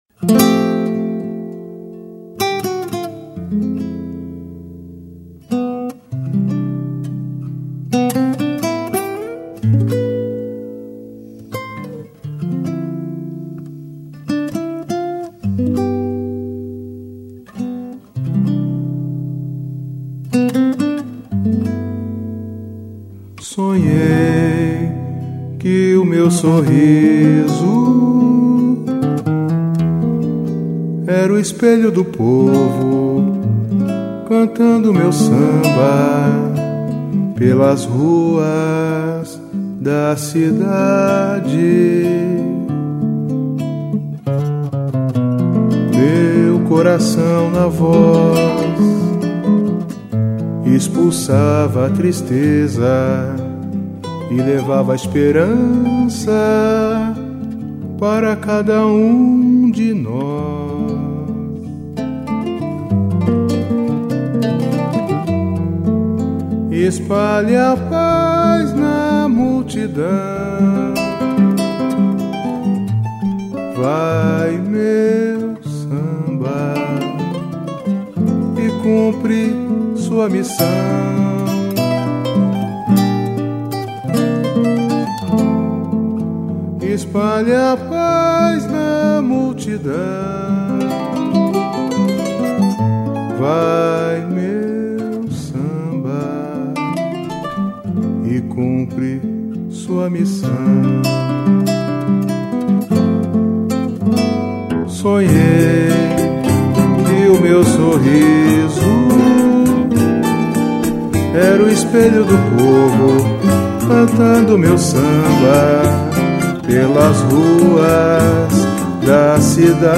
EstiloSamba